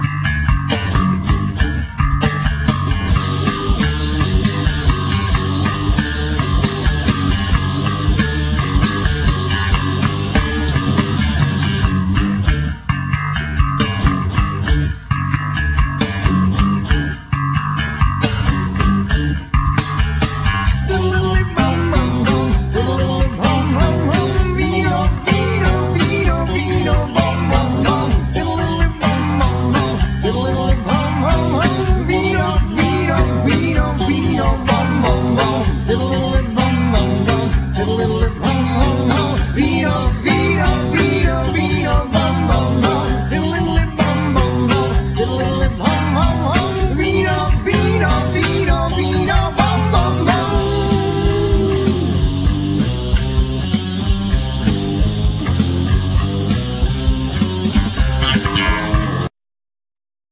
Voice
Bass
Guitar
Cimbalum,Vocal
Saxophone,Bassoon
Violin